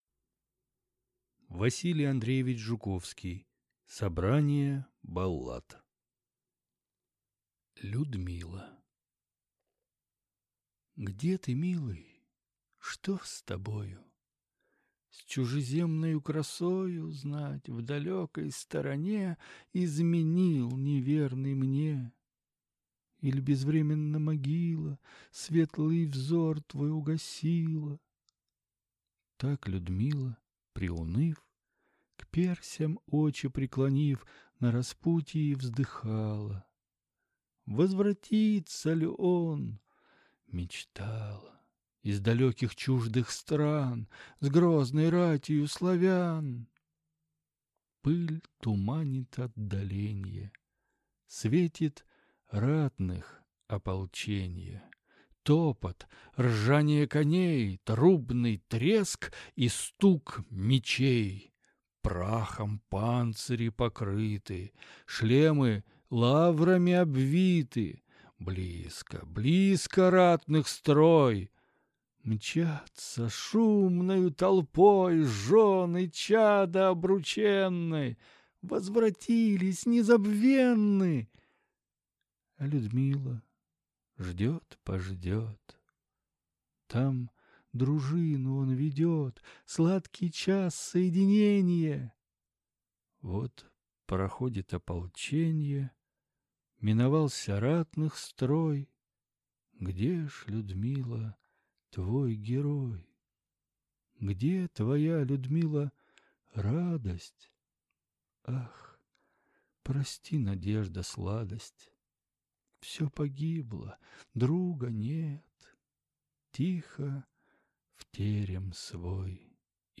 Аудиокнига Собрание баллад | Библиотека аудиокниг